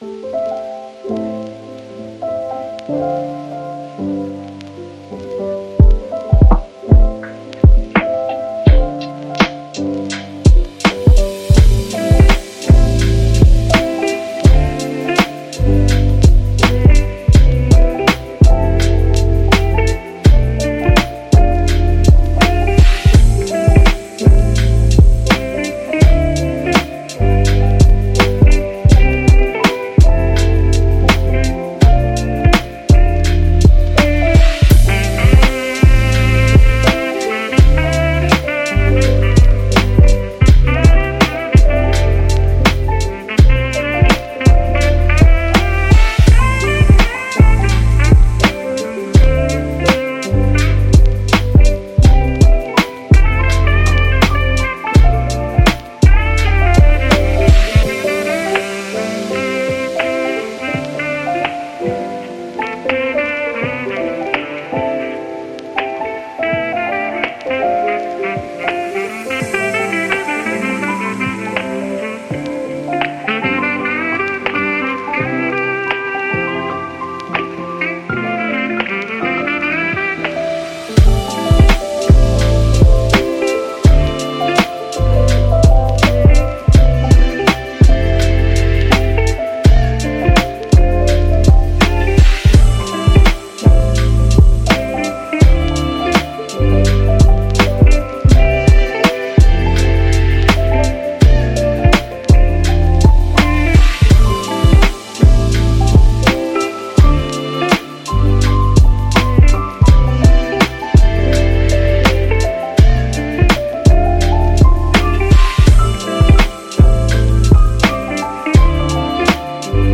Jazz Lofi